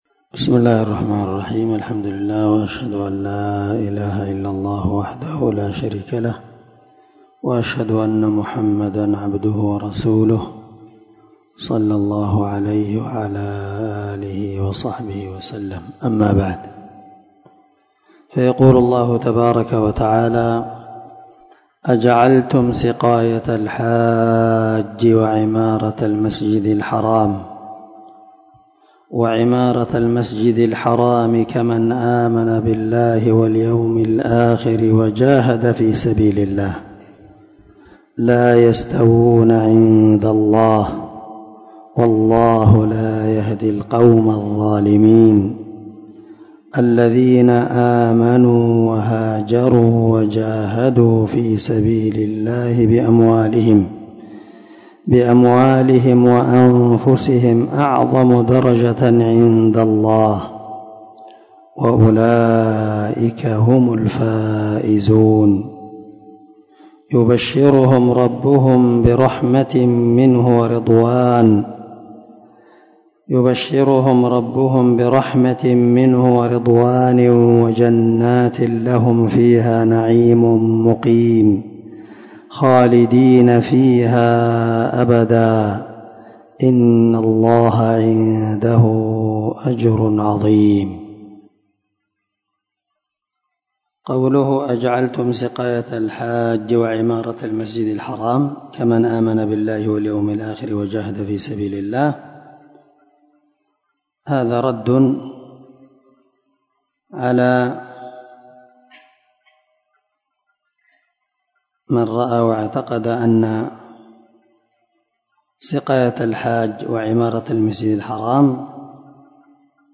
537الدرس9تفسير آية ( 19 – 22 ) من سورة التوبة من تفسير القران الكريم مع قراءة لتفسير السعدي
دار الحديث- المَحاوِلة- الصبيحة.